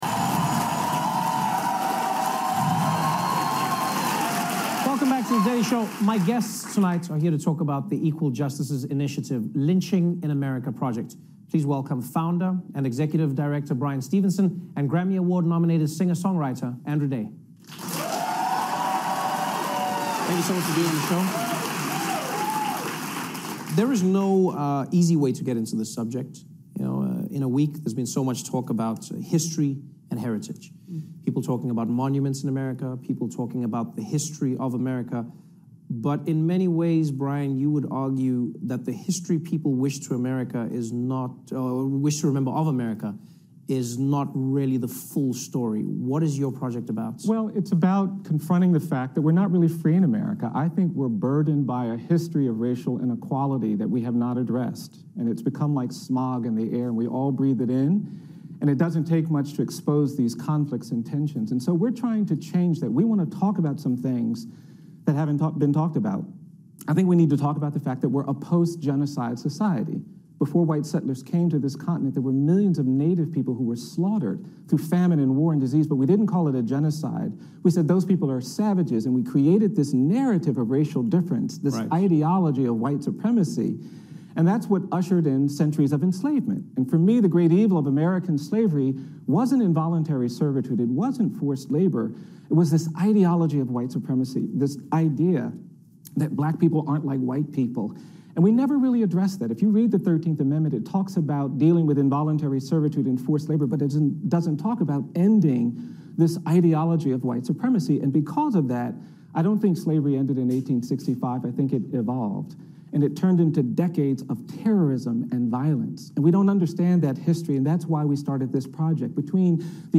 When I watched Trevor Noah in New York City on August 23, 2017, I thought of our conference. Bryan Stevenson of the Equal Justice Initiative spoke and
I recorded his voice for you: